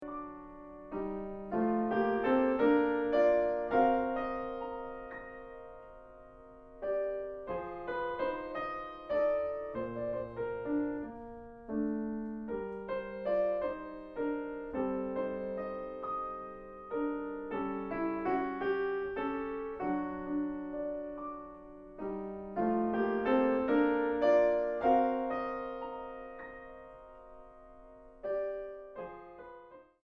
En do menor 1.47